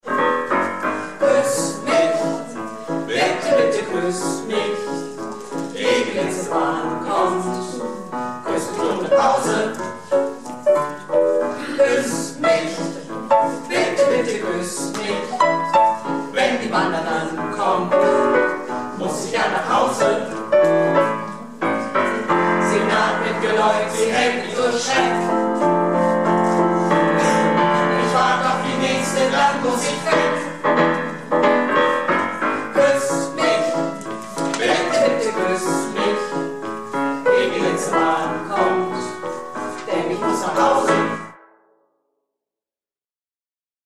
Projektchor "Keine Wahl ist keine Wahl" - Probe am 26.03.19